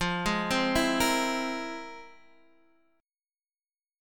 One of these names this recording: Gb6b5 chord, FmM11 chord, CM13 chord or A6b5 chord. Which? FmM11 chord